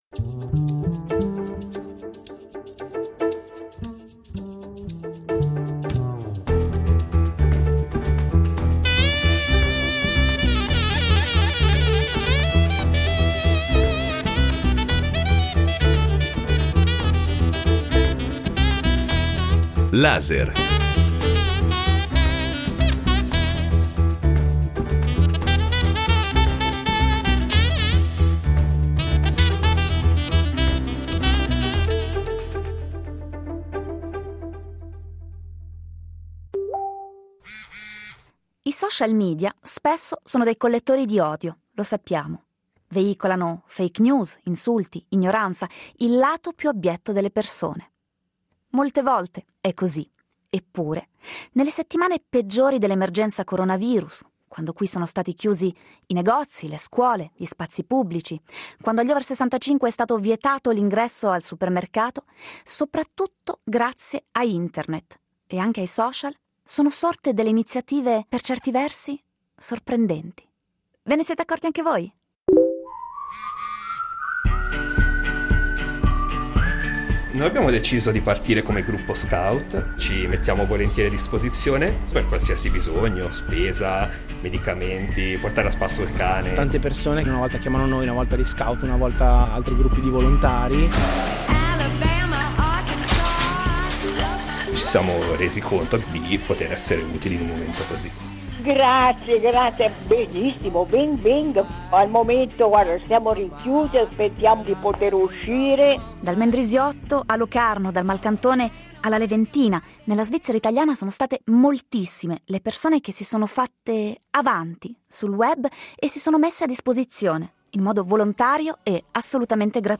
La fase più dura dell’emergenza coronavirus è stata contraddistinta non soltanto dall’isolamento e dalla paura, ma anche dalla solidarietà delle persone che – spesso sfruttando le piattaforme social – si sono fatte avanti per offrire il proprio aiuto. Si tratta di persone comuni, di “guerrieri della luce” che hanno operato nel buio dell’anonimato, dando vita a forme di volontariato e di solidarietà inaspettate e sorprendenti. Queste sono le loro voci e le storie di cui sono state protagoniste.